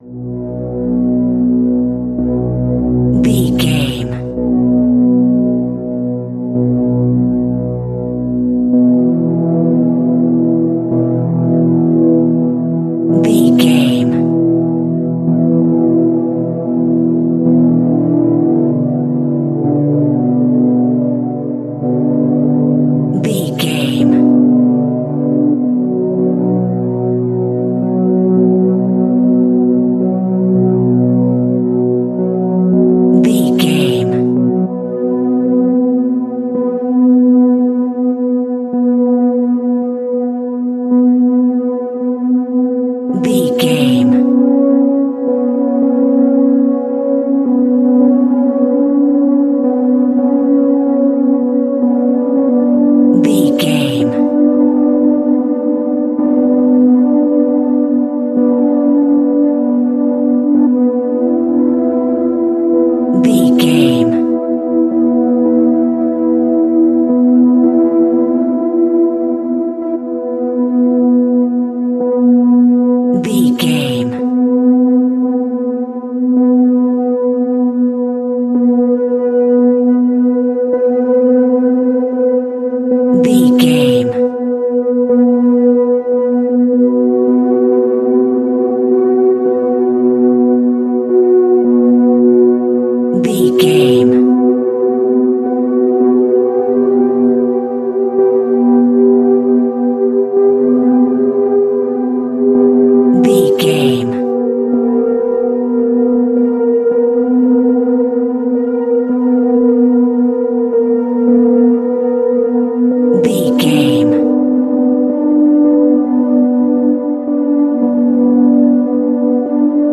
Horror Haunting Pads.
Aeolian/Minor
Slow
tension
ominous
dark
eerie
synthesiser